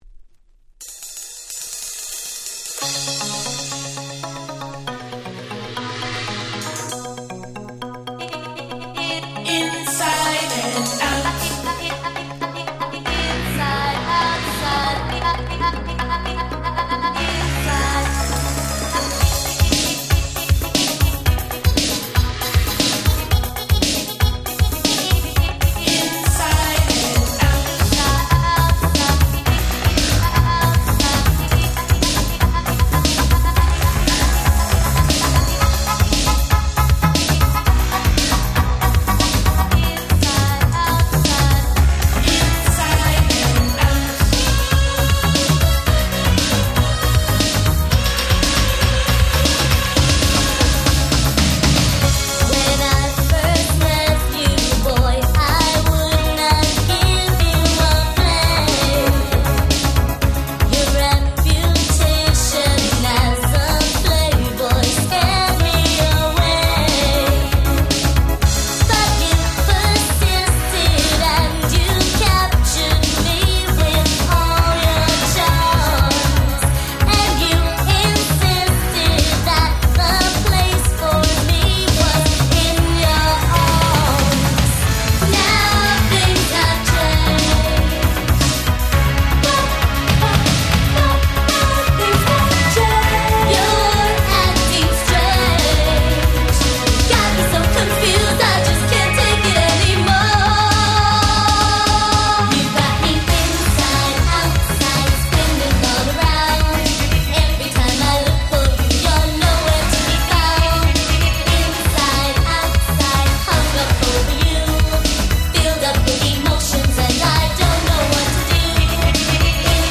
88' Super Hit Disco / R&B !!